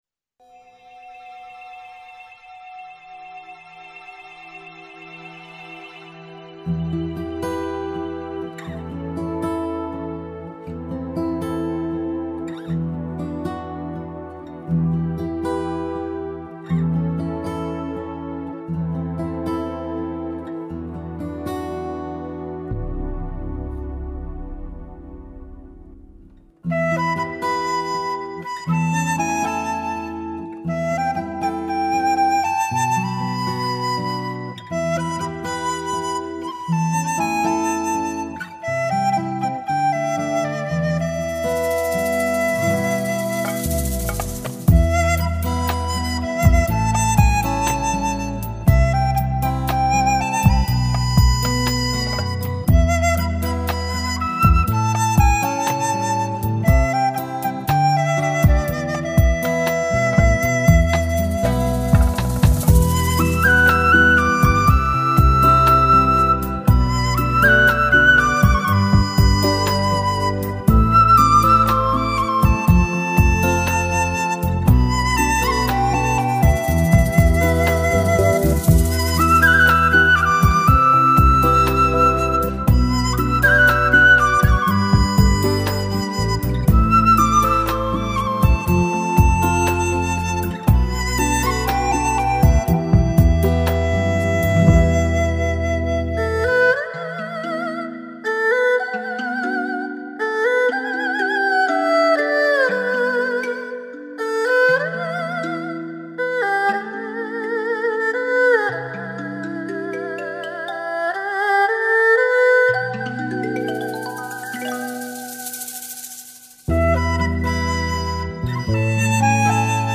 最浑厚的声音，最佳的搭配，联手打造一部来自西藏的声音。
笛子/二胡